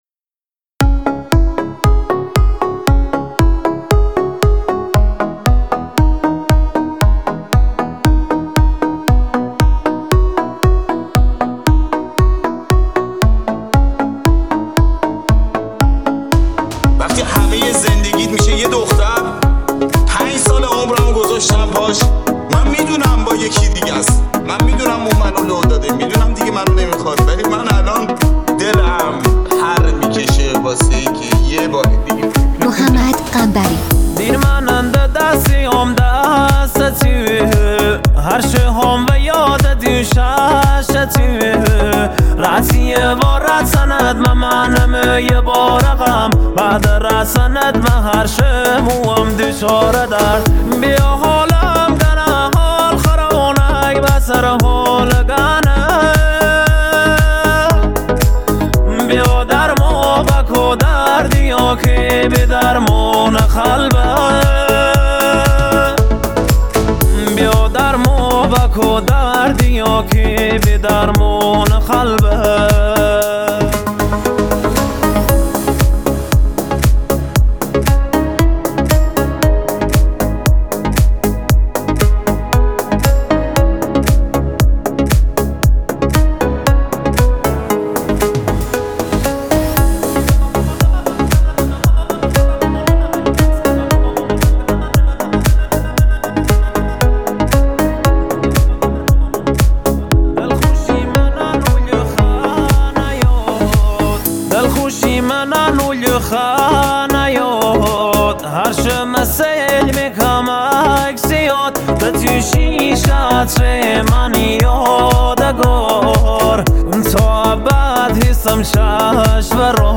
دانلود ریمیکس